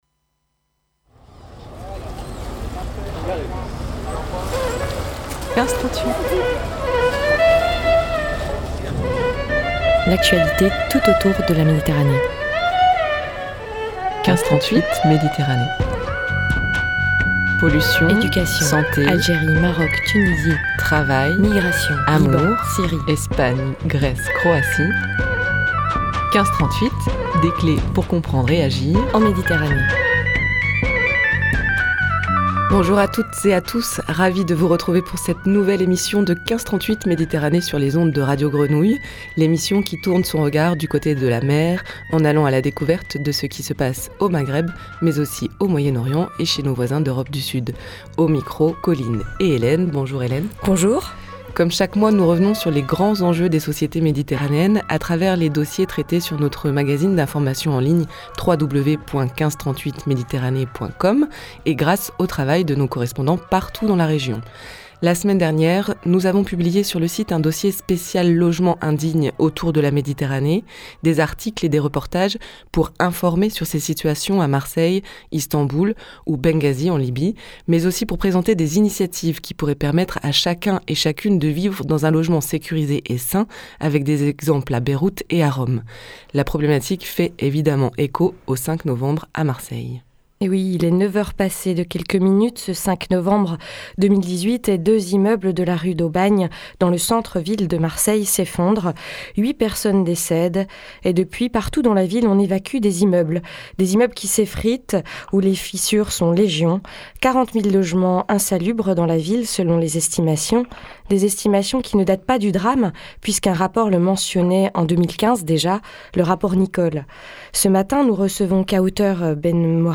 Retrouvez chaque mois des invités, des témoignages, des reportages pour 30 minutes d'émission.